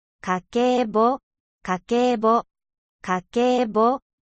Kakebo o ??? [pronunciado kah-keh-boh] y, por si no saben japones, significa "libro de cuentas del hogar".